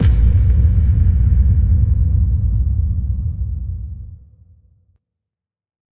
Impact 15.wav